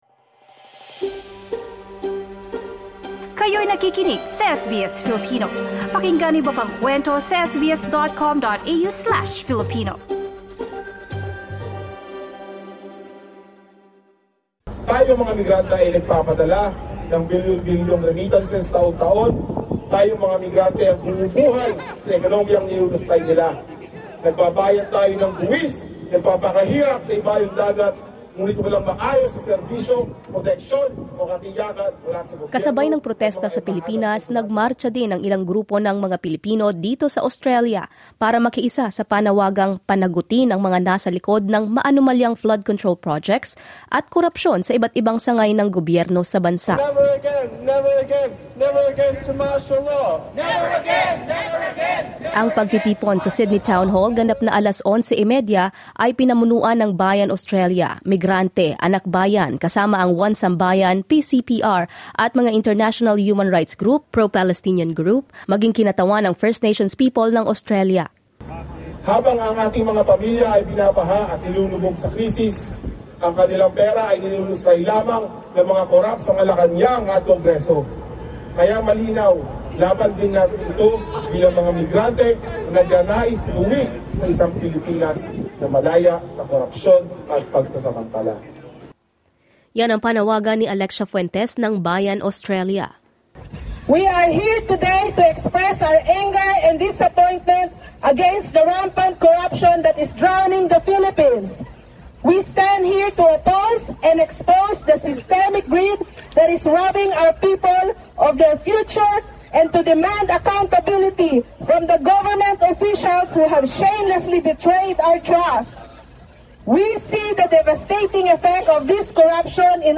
Key Points Chanting “Never Again,” the demonstrators highlighted both historical lessons from Martial Law and contemporary issues of alleged misuse of public funds.